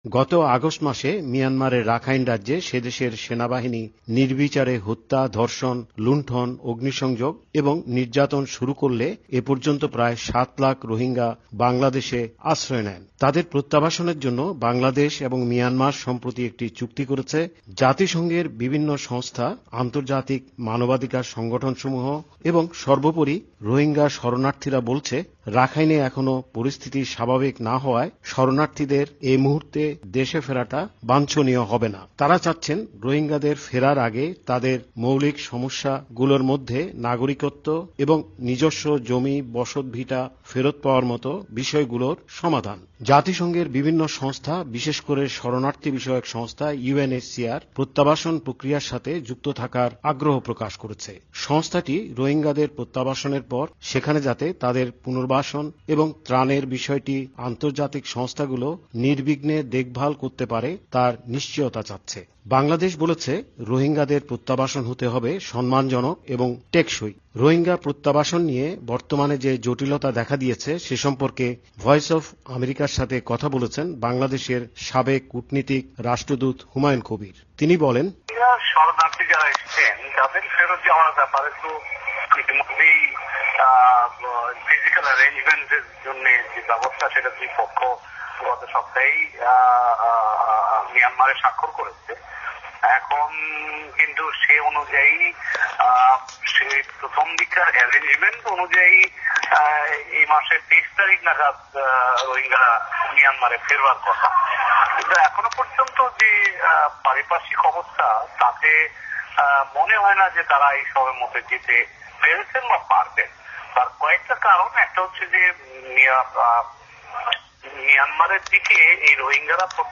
এ বিষয়ে ভয়েস অফ আমেরিকার সাথে কথা বলেছেন সাবেক কূটনীতিক রাষ্ট্রদূত হুমায়ুন কবির।